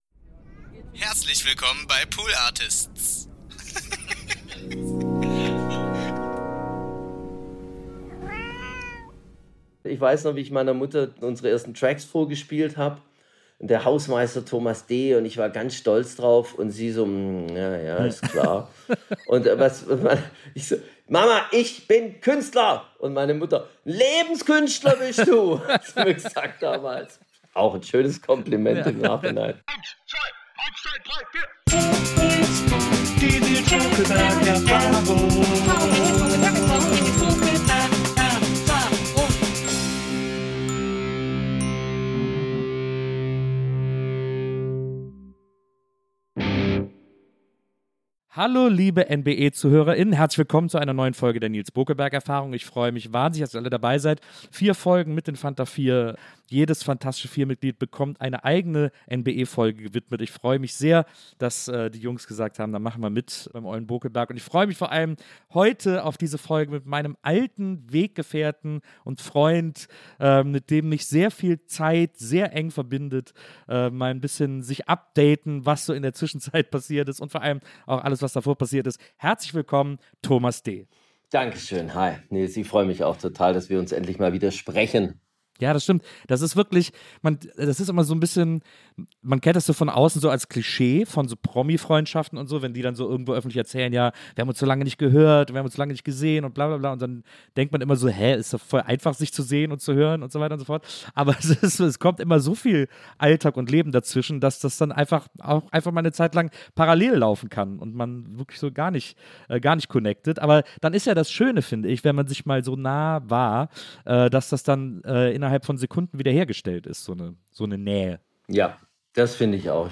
Herzlich Willkommen im Wohnzimmerstudio von Nilz Bokelberg!
Die Frau mit der besten Lache der Welt, Evelyn Weigert moderiert durch diese emotionale und mit Nostalgie gespickte Abschiedsfolge, wodurch Nilz selbst zum Gast seines eigenen Podcasts wird. Beide blicken gemeinsam auf eine aufregende NBE-Zeit voller spannender, schöner, trauriger, aber auch nachdenklicher und ernster Momente zurück. Nebenbei gibt es einige Überraschungen und vor allem viele Sprachnachrichten von ehemaligen Gäst:innen und NBE-Teammitgliedern zu hören.